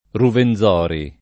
vai all'elenco alfabetico delle voci ingrandisci il carattere 100% rimpicciolisci il carattere stampa invia tramite posta elettronica codividi su Facebook Ruwenzori [ ruven z0 ri ; ingl. ruuën @0 ori ] → Ruvenzori